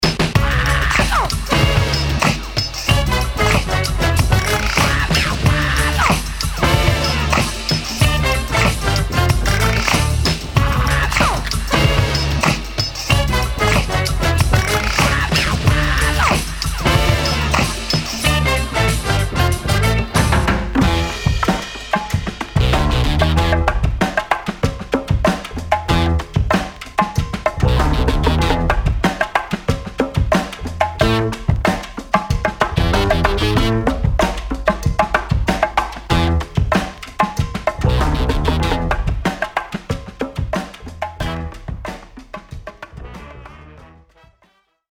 ジャンル(スタイル) JAPANESE HIP HOP